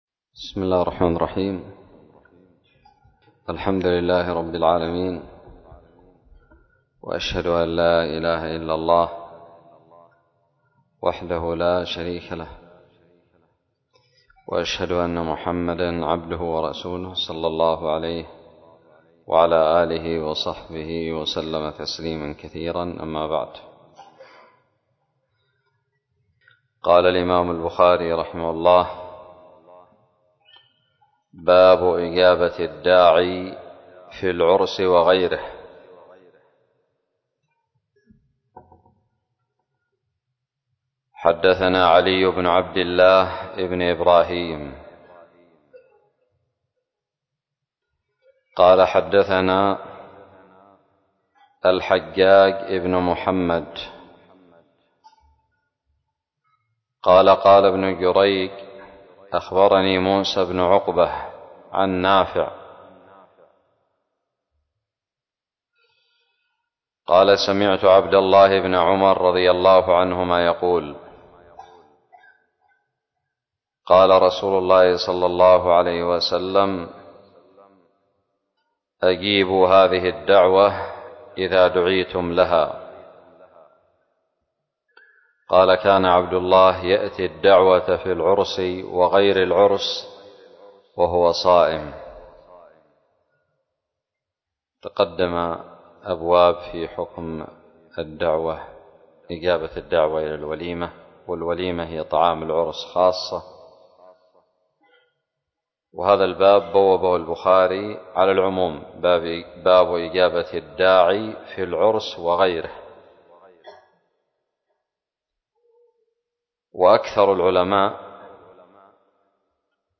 شرح صحيح الإمام البخاري- متجدد
ألقيت بدار الحديث السلفية للعلوم الشرعية بالضالع